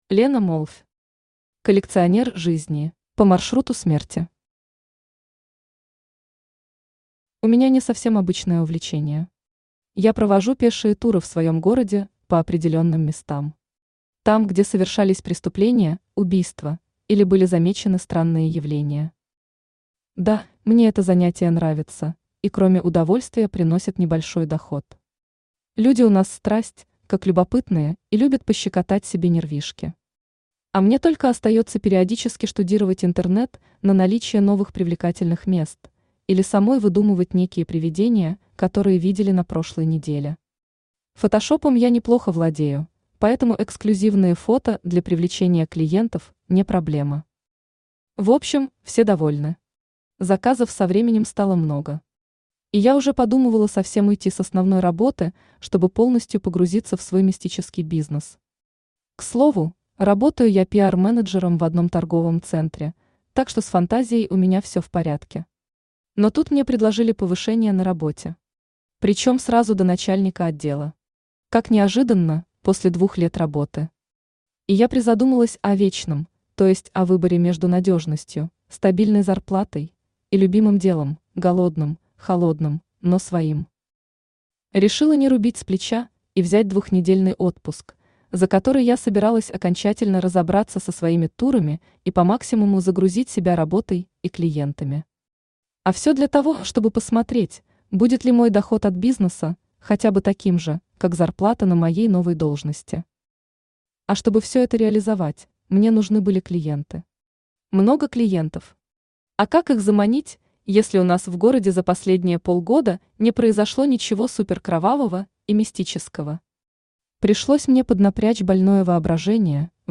Аудиокнига Коллекционер жизней | Библиотека аудиокниг
Aудиокнига Коллекционер жизней Автор Лена Молвь Читает аудиокнигу Авточтец ЛитРес.